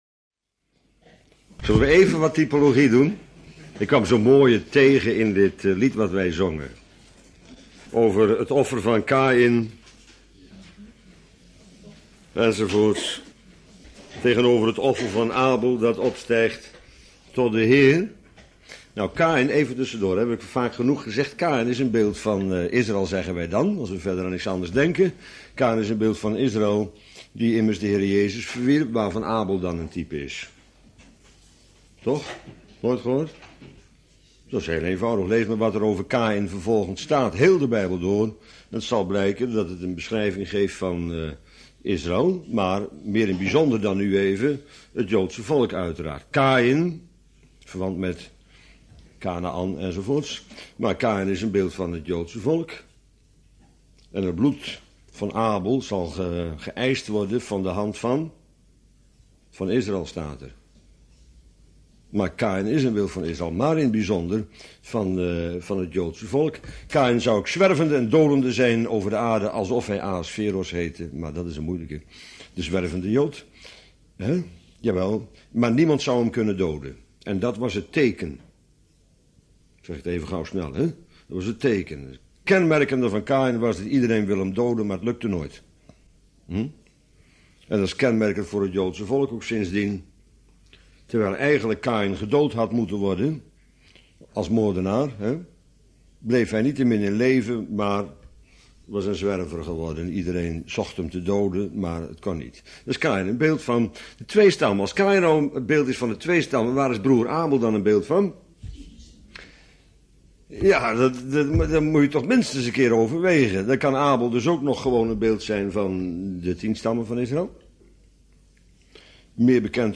Bijbelstudie lezingen mp3.